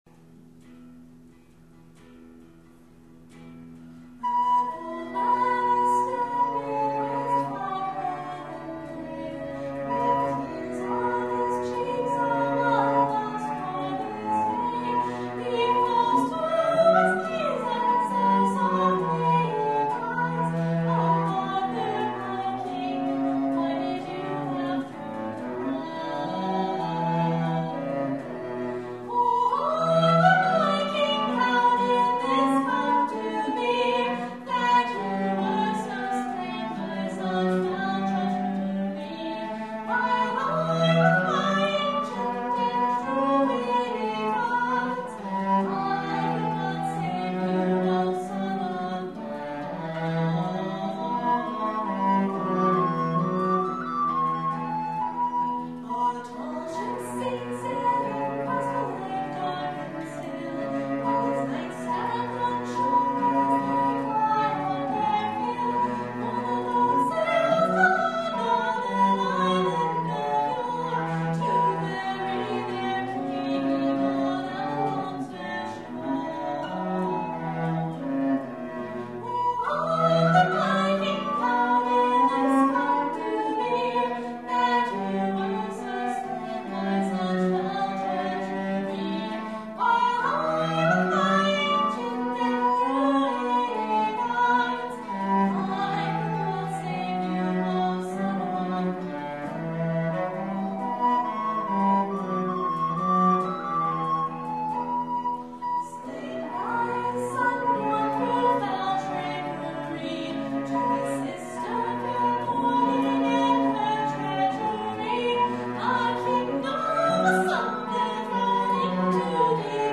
The version of this song on this website is a live performance I did for my minor in music theory.  Apologies for the poor sound quality.